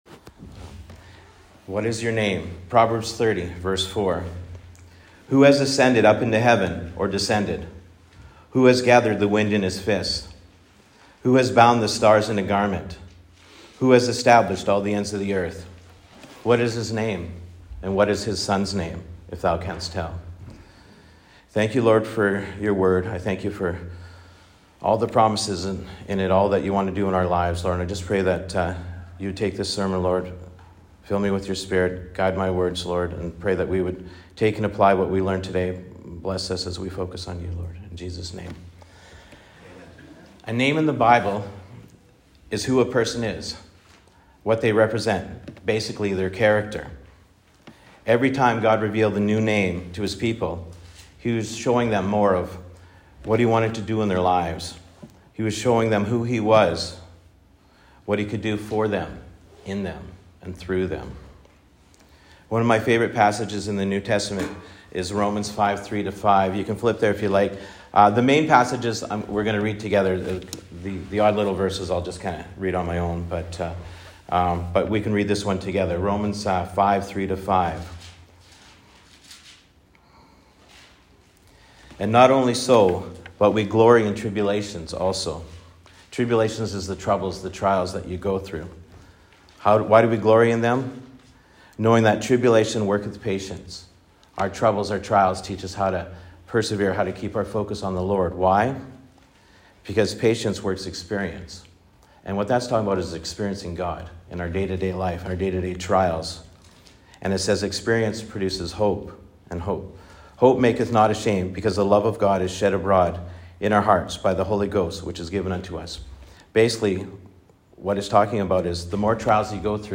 Poems Presented In Church
In 2021 to 2022, for about 10 months in the two main Sunday services, I presented poems in church as a ministry. Usually I shared an introduction to the poem (perhaps the story behind it if it was one of mine or if I knew of the events surrounding it), then I presented the verses behind it and a Biblical exhortation to draw closer to the Lord.